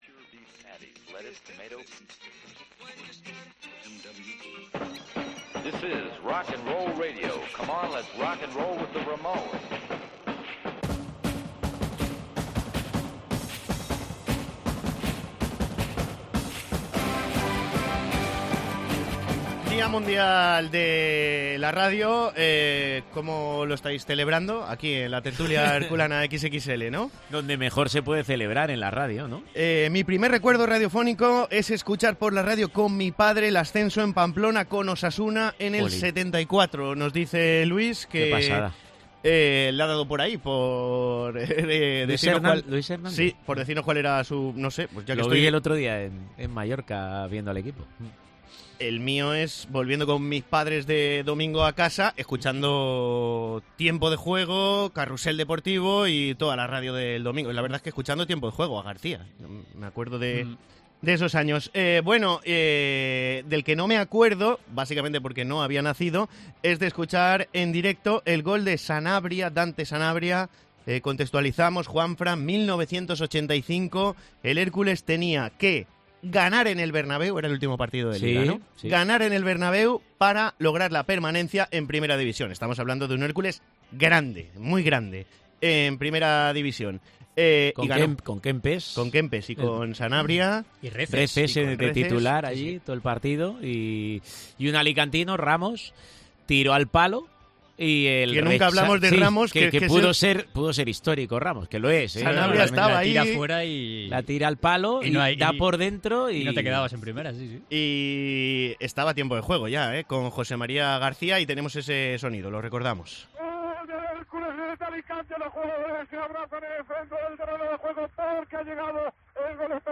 Hablamos de la actualidad del Hércules y recordamos algunos de los sonidos más destacados de la historia de Tiempo de Juego en clave blanquiazul.